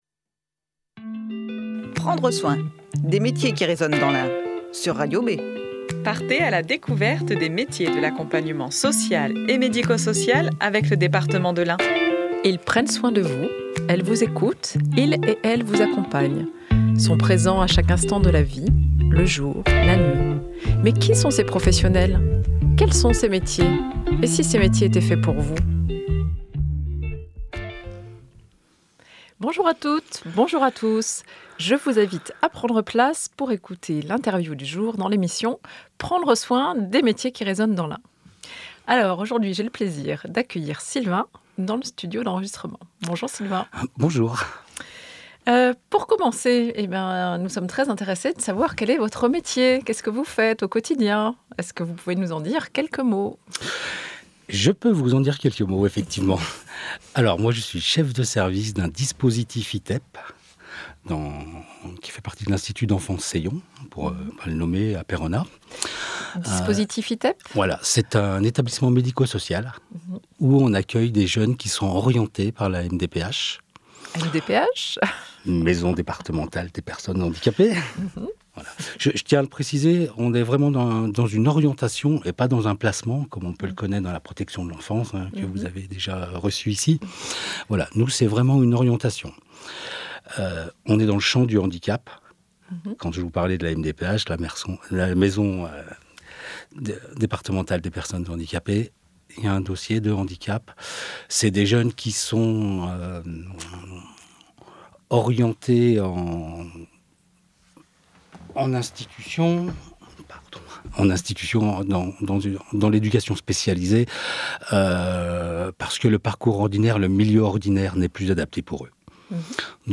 Tous les troisièmes vendredis du mois, retrouvez une "interview minute" avec un professionnel des métiers de l'accompagnement social et médico-social.